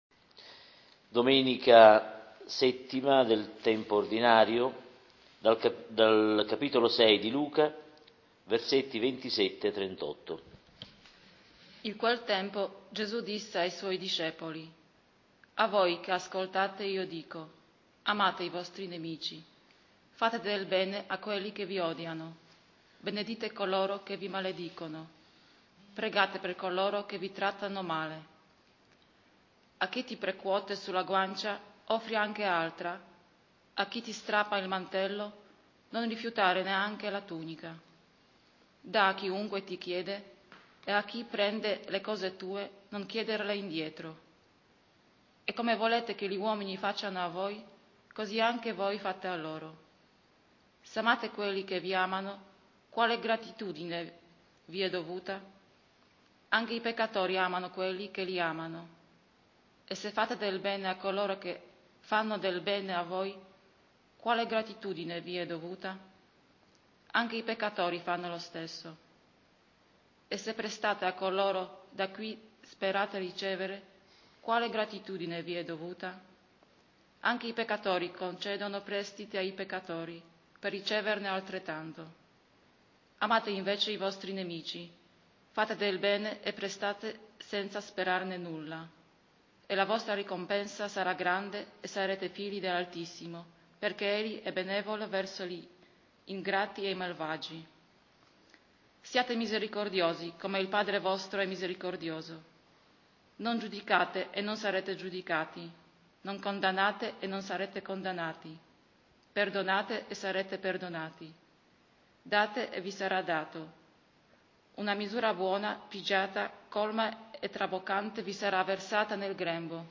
Lectio divina DOMENICA del «SIATE MISERICORDIOSI», VII del Tempo per l’Anno C